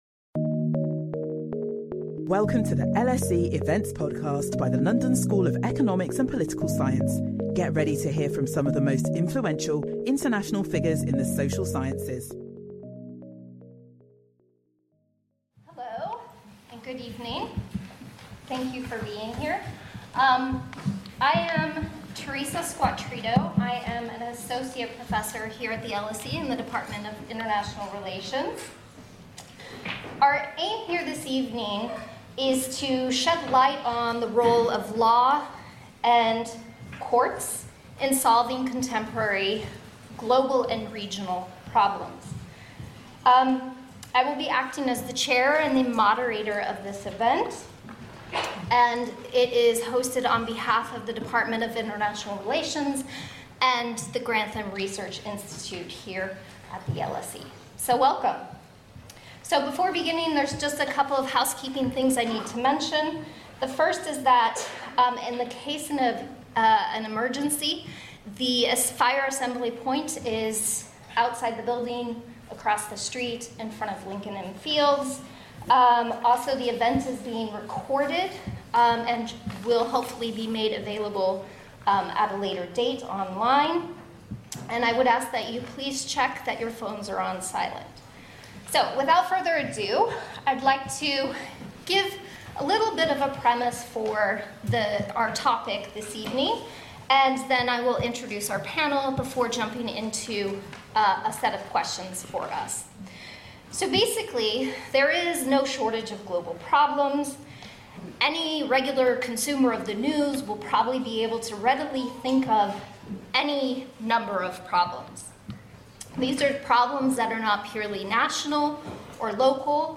There is a growing expectation for law and courts, whether domestic or international, to be remedies for international problems. Our panel explore the power of law and courts in the face of contemporary international challenges.